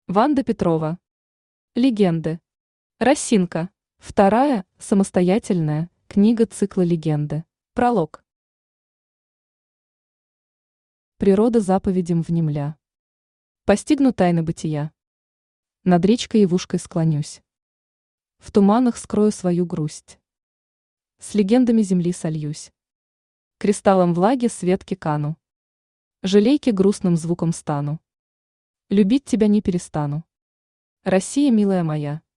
Аудиокнига «Легенды». Росинка | Библиотека аудиокниг
Росинка Автор Ванда Михайловна Петрова Читает аудиокнигу Авточтец ЛитРес.